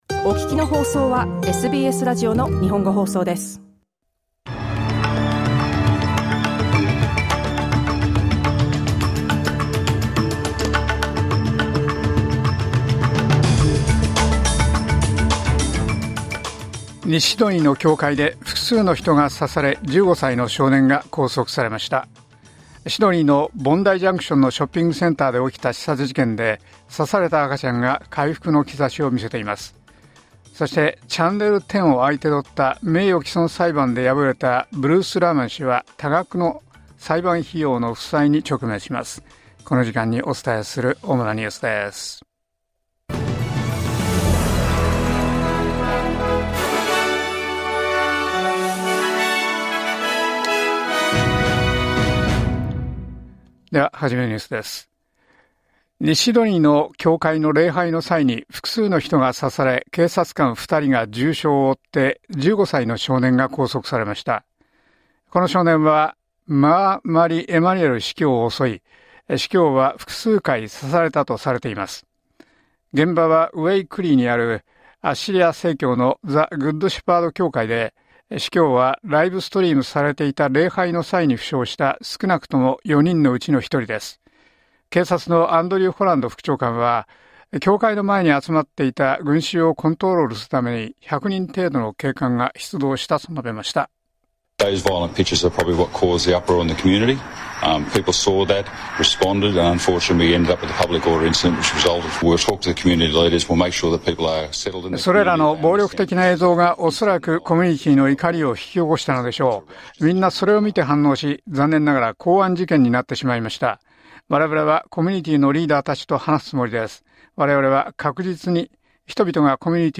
SBS日本語放送ニュース４月16日火曜日